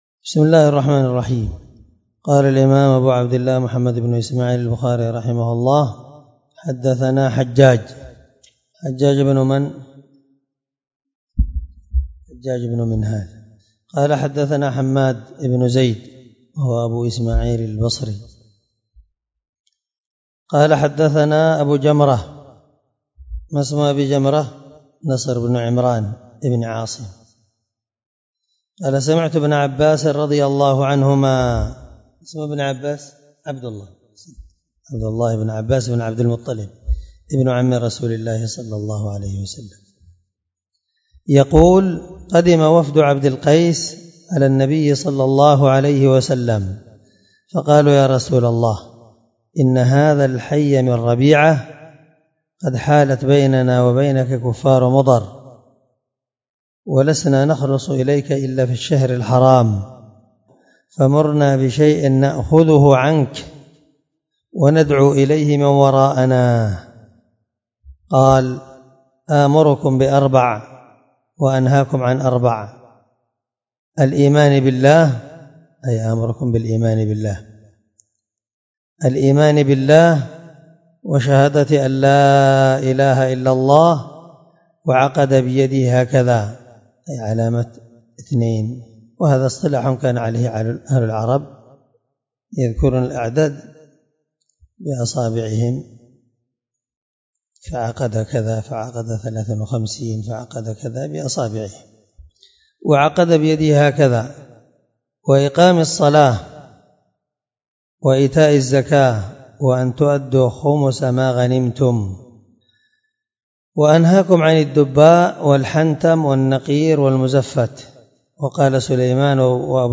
الدرس 3من شرح كتاب الزكاة حديث رقم(1398-1400 )من صحيح البخاري